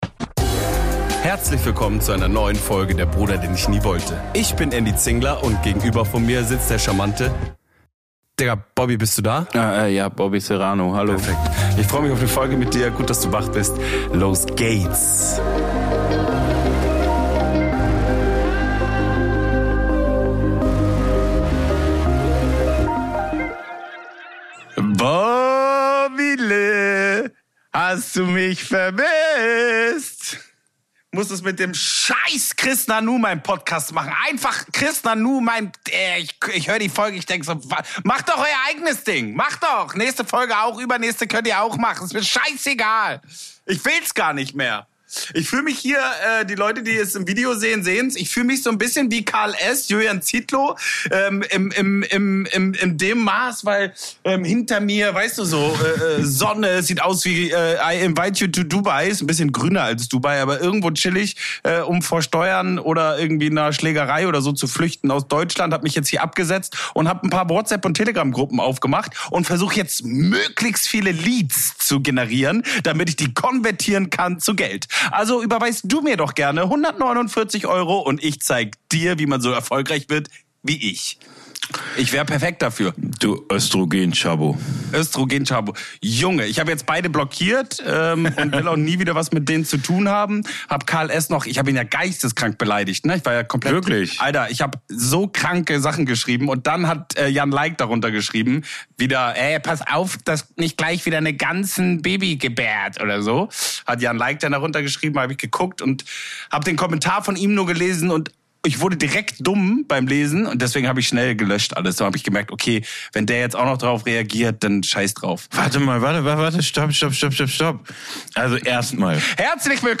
Ungefiltert, laut und endgültig.